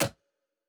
pgs/Assets/Audio/Fantasy Interface Sounds/UI Tight 21.wav at master
UI Tight 21.wav